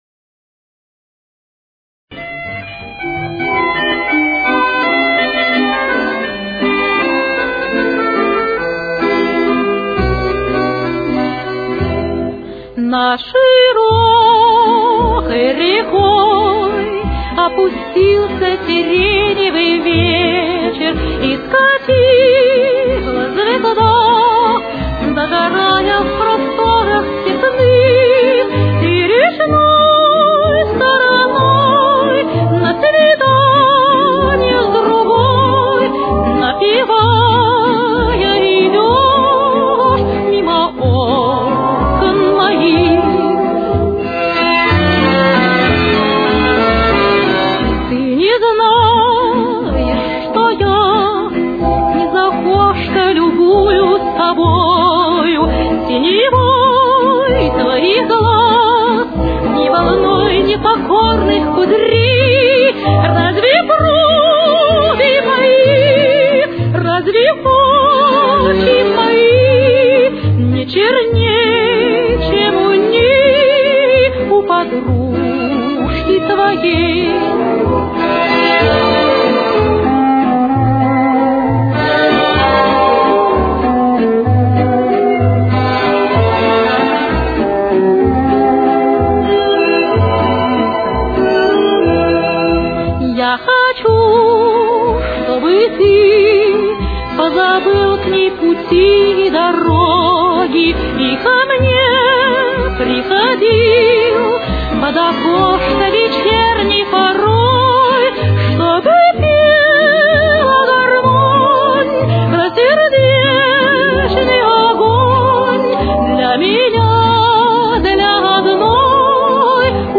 Темп: 185.